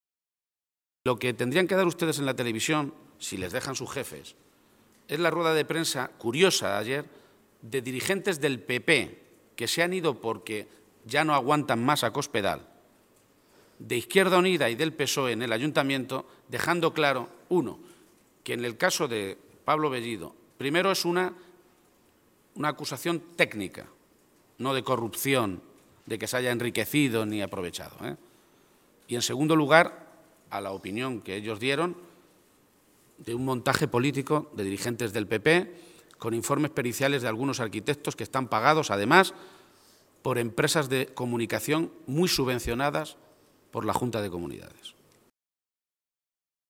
García-Page se pronunciaba de esta manera esta mañana, en Toledo, a preguntas de los medios de comunicación en la que se le preguntaban por varios asuntos de actualidad.
Cortes de audio de la rueda de prensa